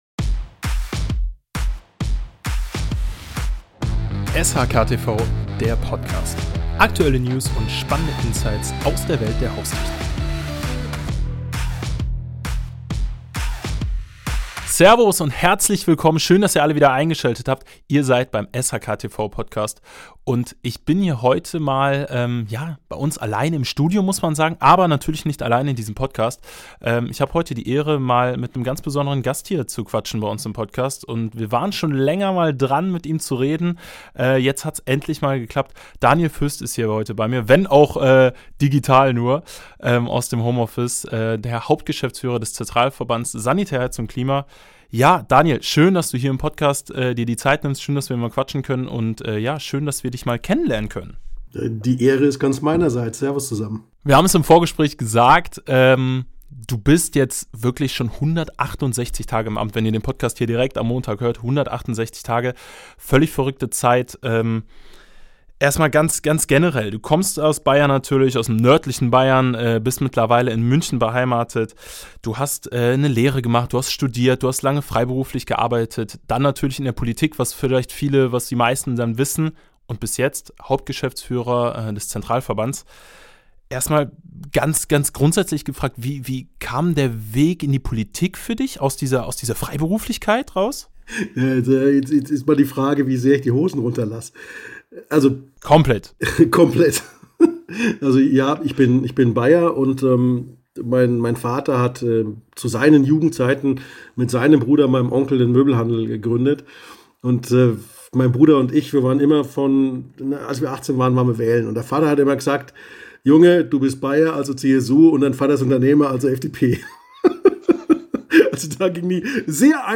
Daniel Föst im Gespräch (#118) ~ SHK-TV Der Haustechnik-Podcast Podcast